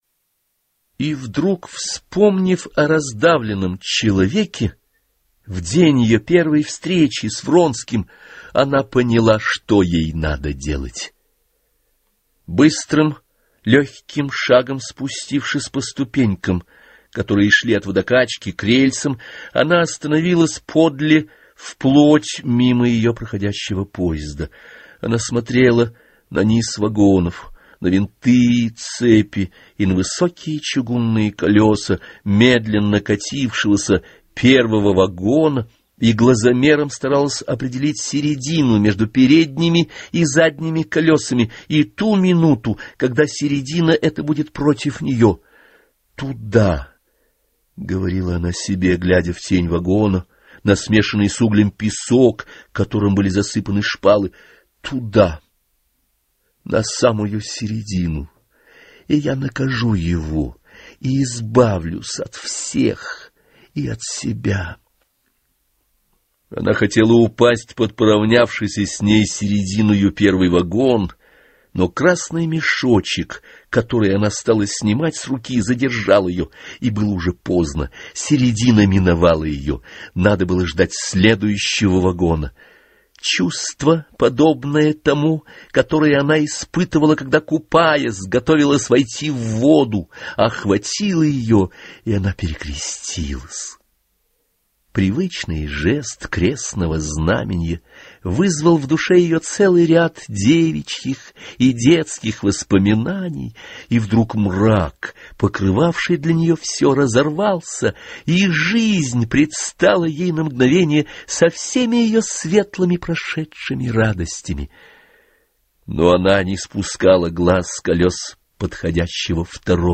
Anna_karenina_audbook.ogg